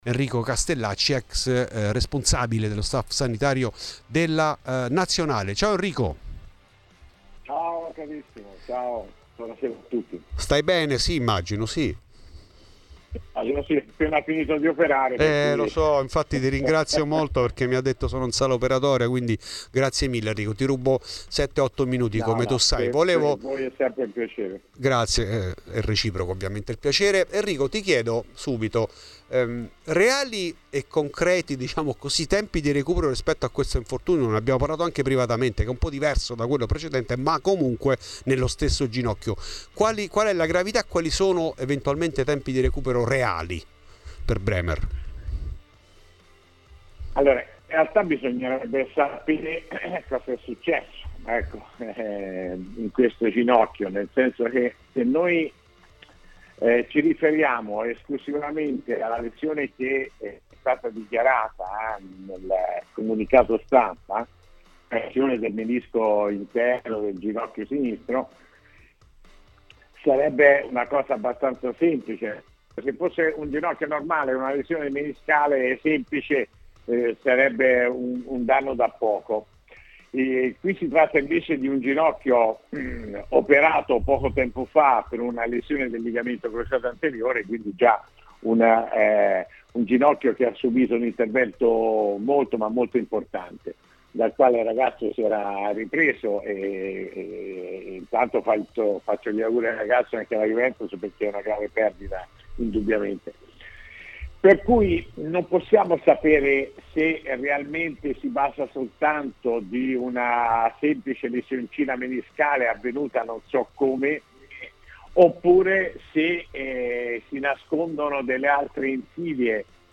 A Fuori di Juve, trasmissione di Radio Bianconera